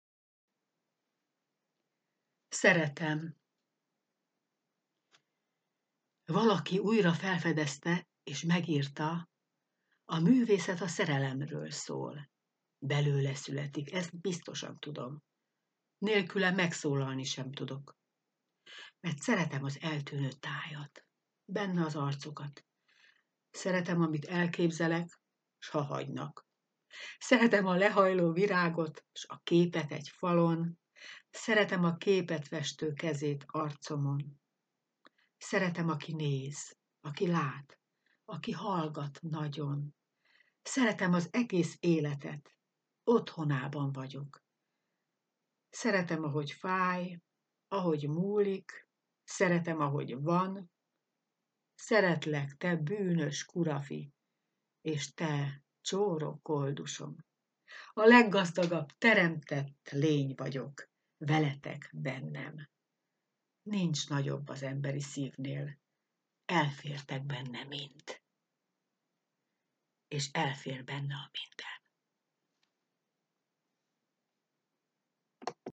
Mondott vers:
Elmondja a szerző.